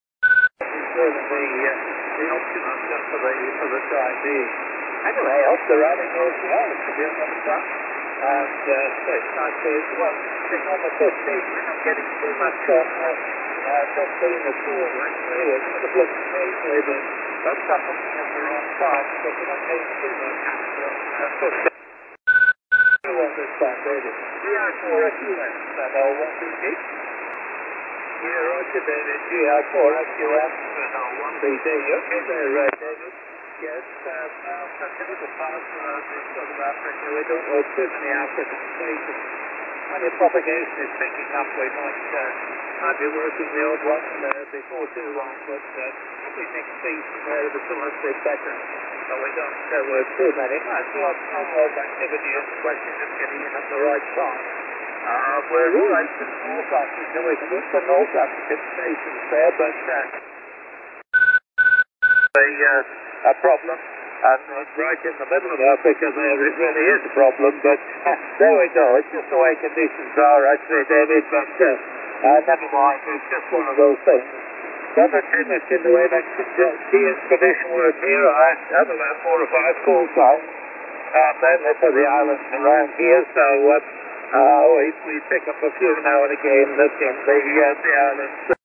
I’ve deliberately selected signals right on the limit, to show the capabilities of the aerials, after all, there’s little point in comparing strength 9 signals on the doorstep.
I have marked this with one ‘beep’ in the recordings.
I’ve marked the SRC X80 with two ‘beeps’ in the recordings.
I’ve marked this with three ‘beeps’ in the recordings.